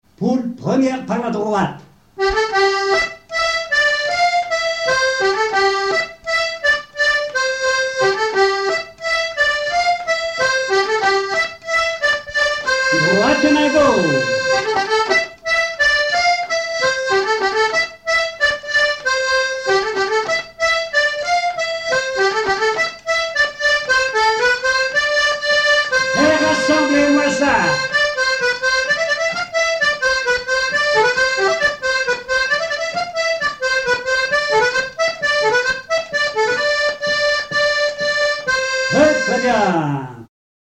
danse : quadrille : poule
Pièce musicale inédite